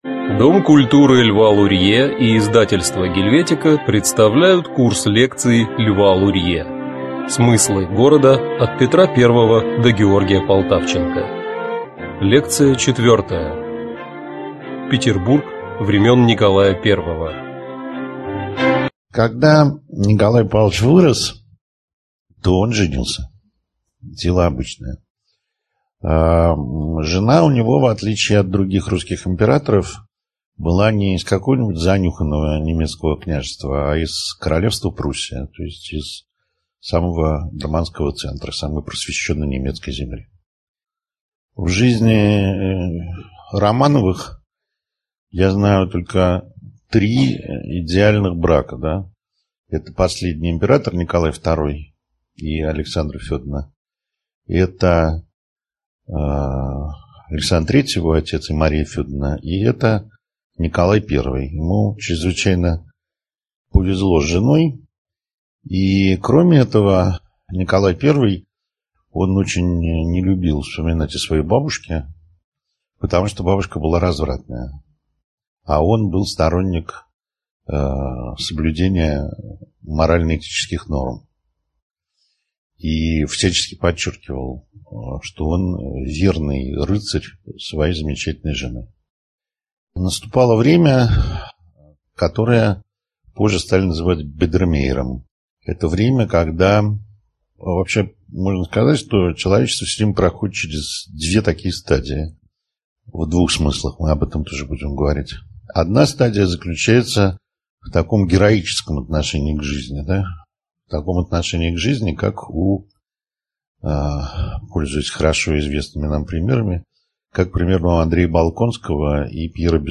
Аудиокнига Лекция 4: Петербург времен Николая I | Библиотека аудиокниг